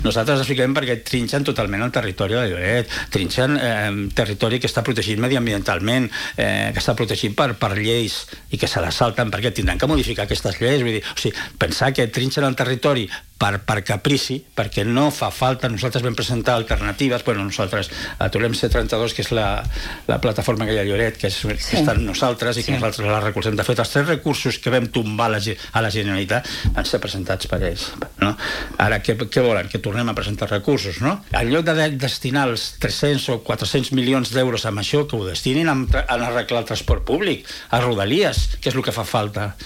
Aquest dilluns, a l’entrevista del matinal de RCT